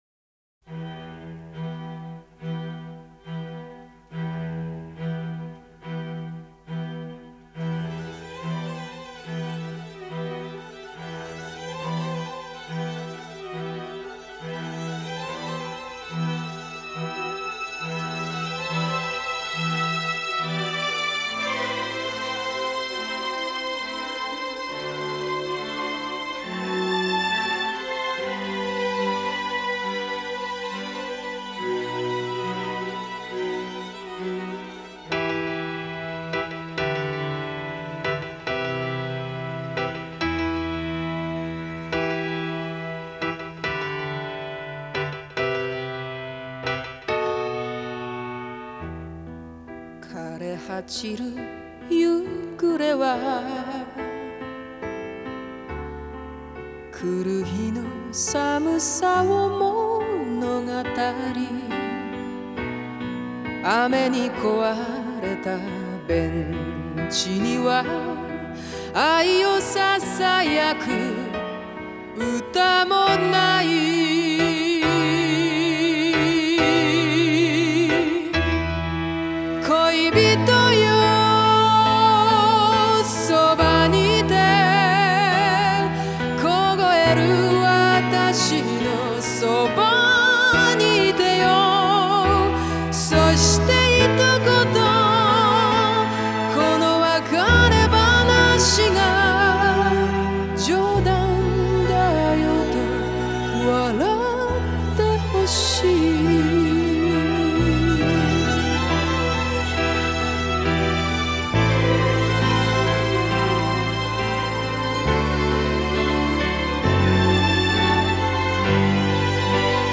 ジャンル： Japanese Pop